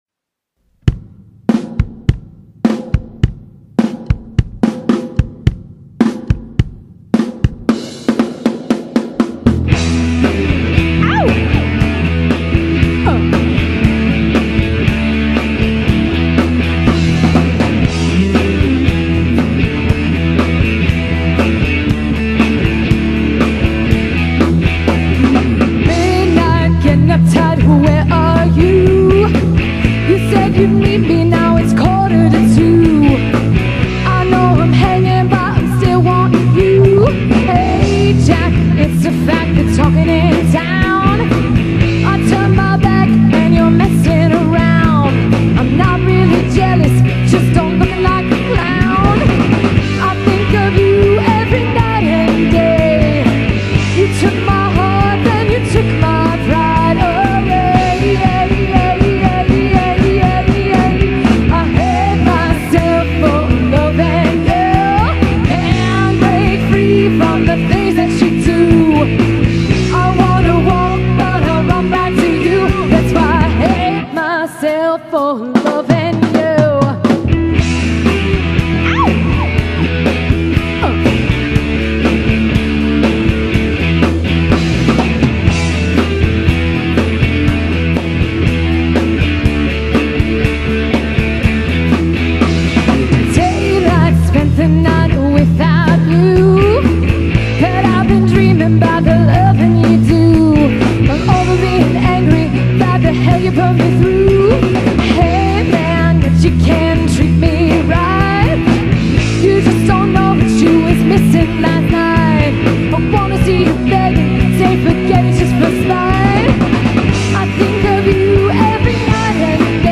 Here are a couple of other live recordings: